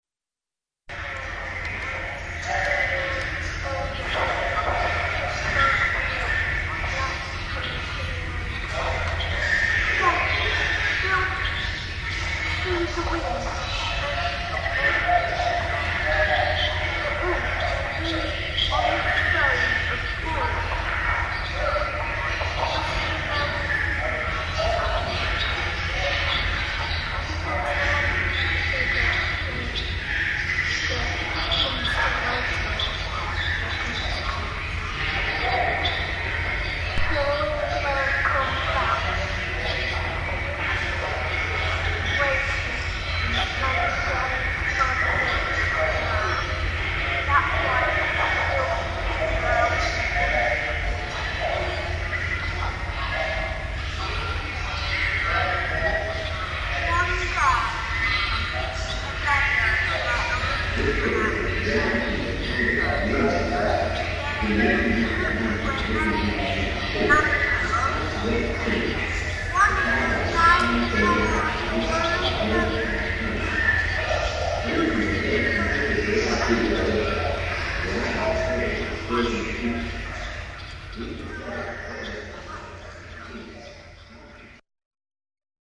As one enters the orientation hall,  a blend of voices and abstract soundscapes emanates from speakers enclosed within the facing wall.  Walking with ones ear close to the wall,  individual stories and sounds can be deciphered.
Pairing each story with a dating graph of an object the same age as the story,  and affecting the file over and over again,  the stories disintegrate into pure rhythm and white noise over the cause of a day.
Once fully disintegrated,  the sound slowly repairs itself,  eventually morphing back into the initial clean recording.
A short recording of the sound in the space, with the 16 channels intermingled: